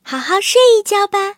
M22蝉中破修理语音.OGG